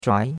zhuai2.mp3